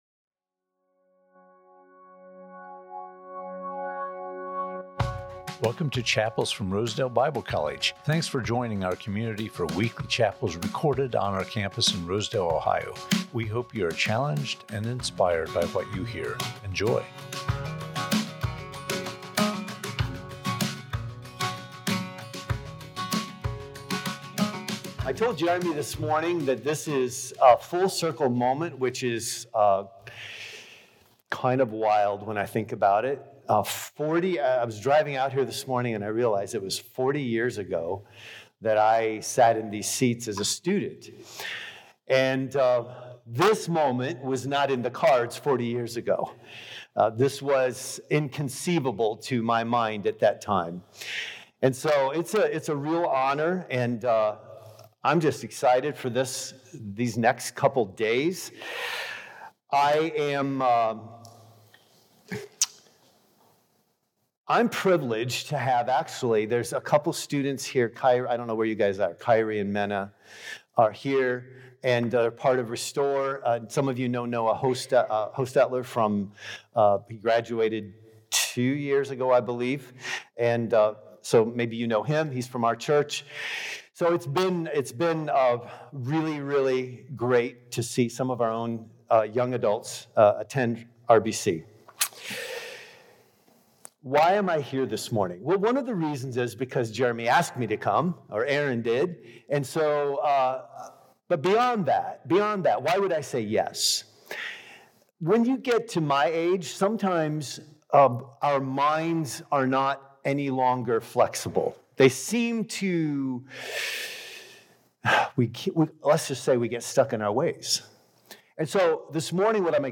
Chapels from Rosedale Bible College